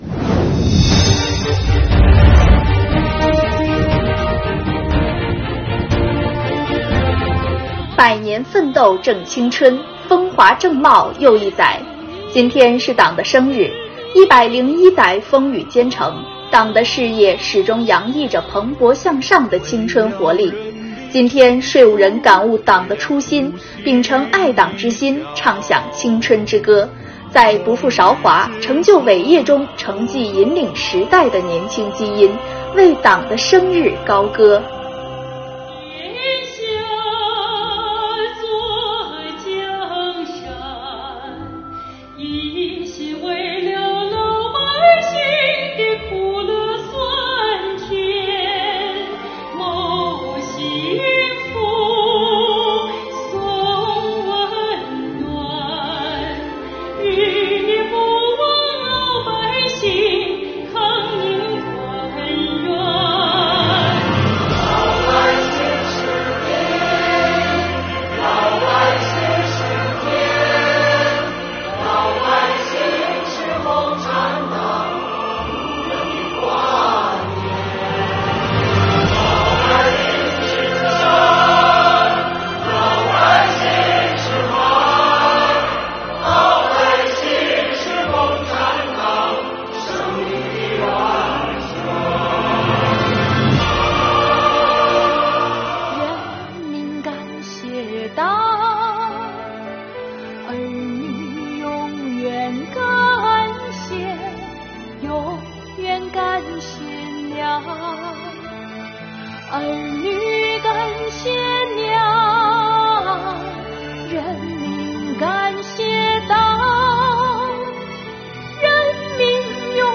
今天，税务人感悟党的初心，秉承爱党之心，唱响青春之歌，在不负韶华、成就伟业中承继引领时代的“年轻基因”，为党的生日高歌。